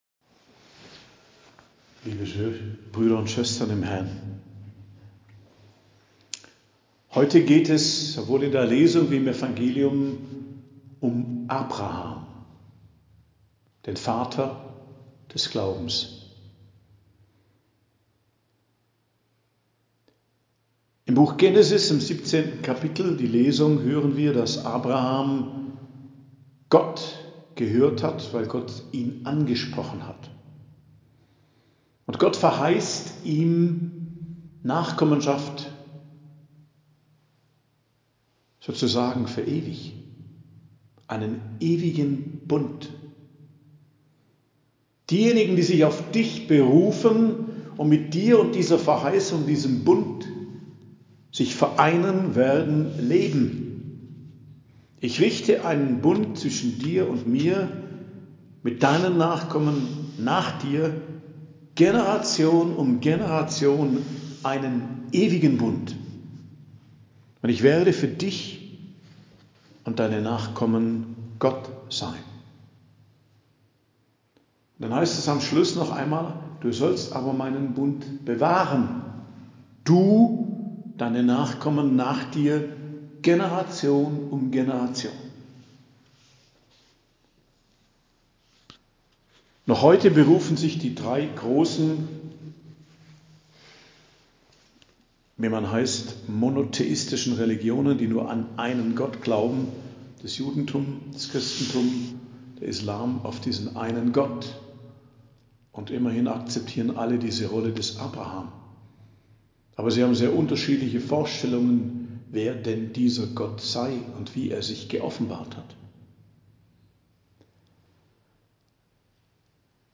Predigt am Donnerstag der 5. Woche der Fastenzeit, 21.03.2024